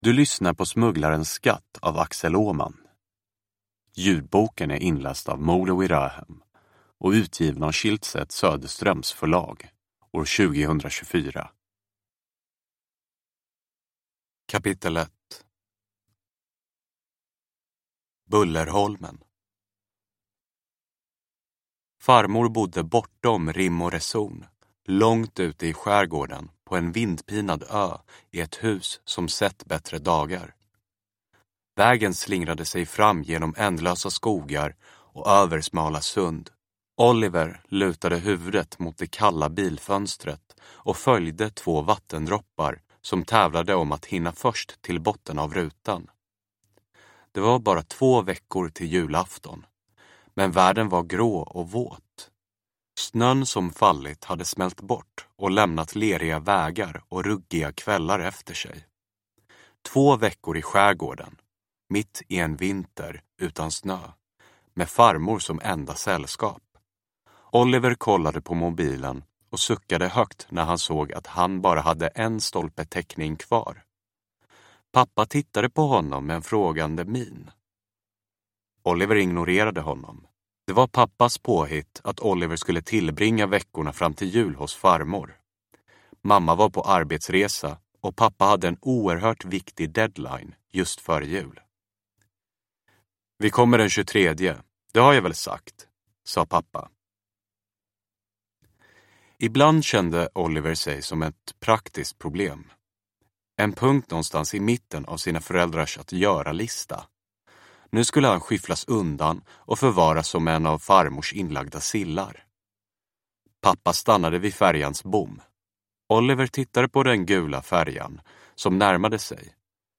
Smugglarens skatt – Ljudbok